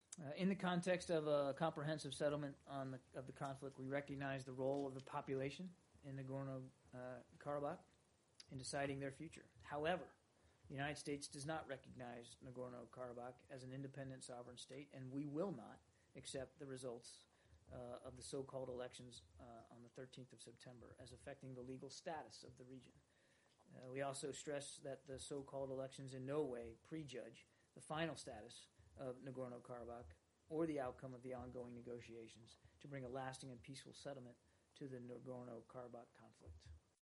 ABŞ Dövlət Departamenti Birləşmiş Ştatların Dağlıq Qarabağda sentyabrın 13-də keçirilməsi planlaşdırılan bələdiyyə seçkilərinin nəticələrini qəbul etməyəcəyini bildirib. Dövlət Departamentinin sözçüsü Con Körbi brifinq zamanı Birləşmiş Ştatların Dağlıq Qarabağı tanımadığı ilə bağlı rəsmi mövqeyini bir daha bəyan edib.